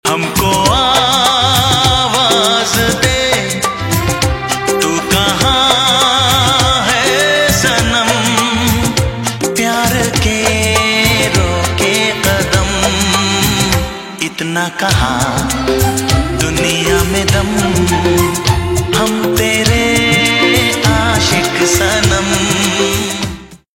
Bollywood 4K Romantic Song